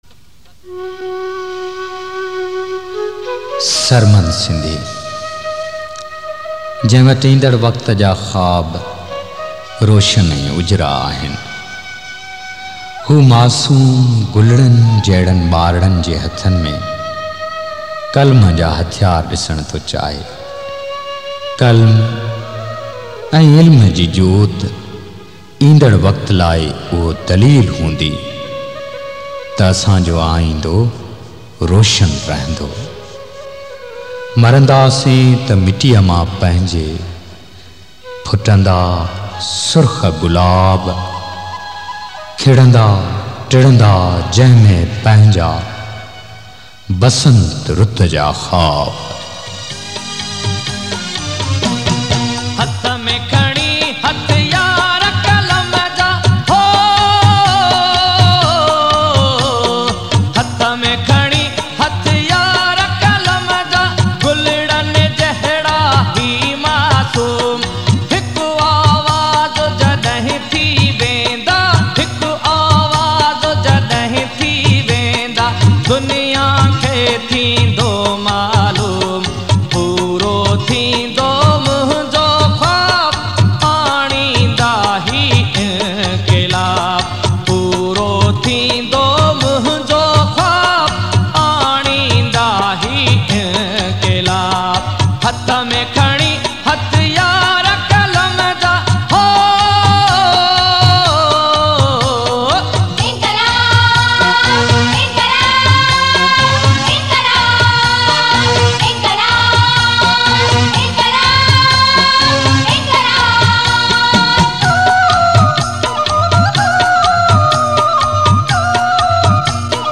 Genre: Sindhi